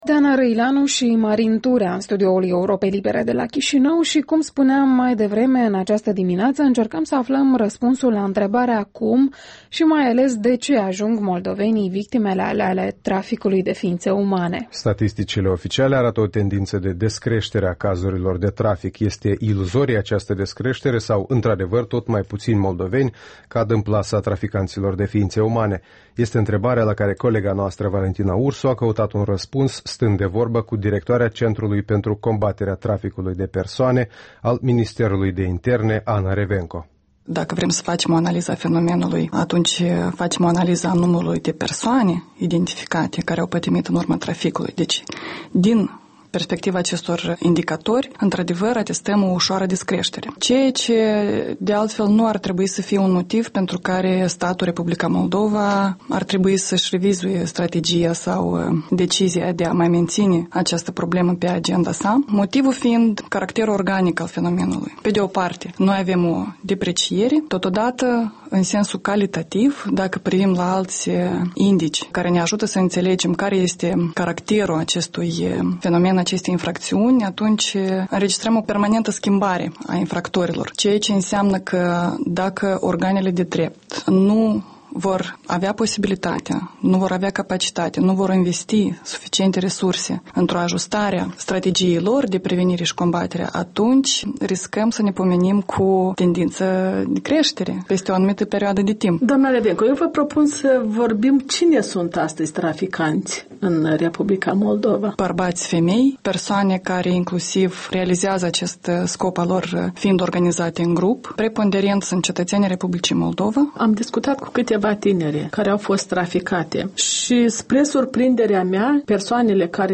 Interviul dimineții: cu Ana Revenco, directoarea Centrului pentru Combaterea Traficului de Persoane